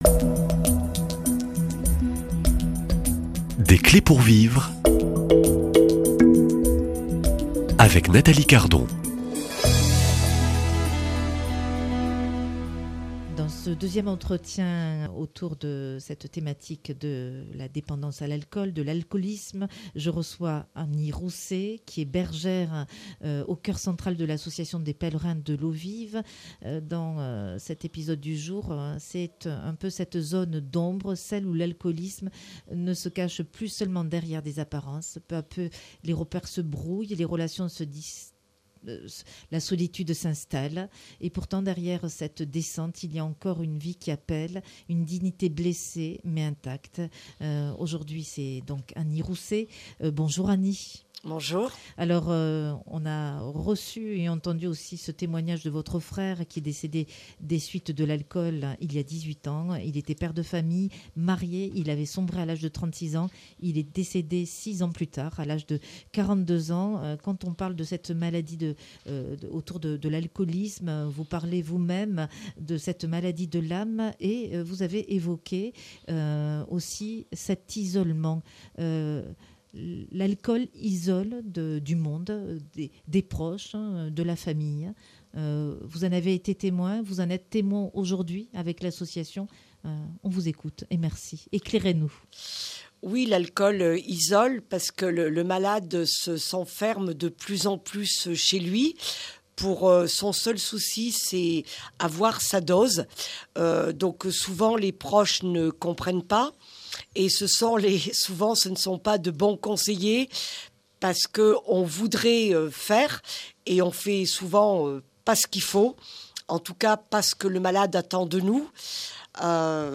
Dans ce deuxième entretien, nous entrons dans la zone d’ombre. Celle où l’alcoolisme ne se cache plus seulement derrière des apparences. Peu à peu, les repères se brouillent, les relations se distendent, la solitude s’installe...